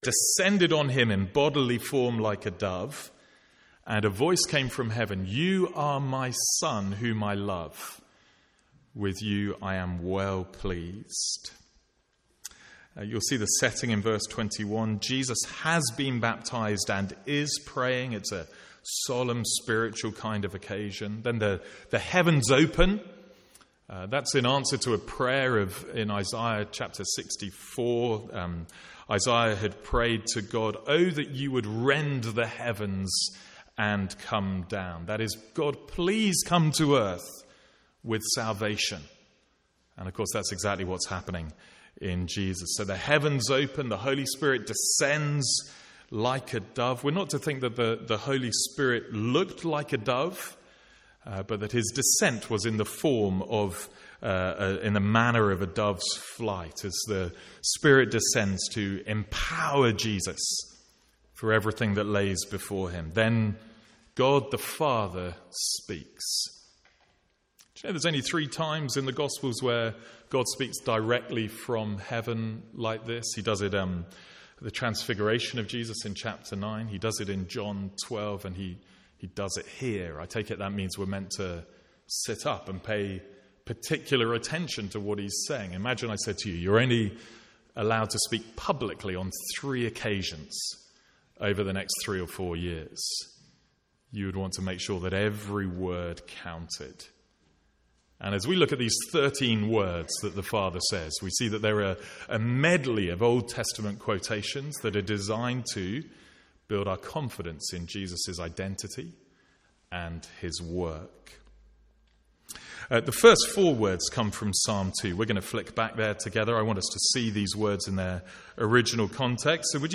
Sermons | St Andrews Free Church
From the Sunday morning series in Luke. (First minute missing from recording).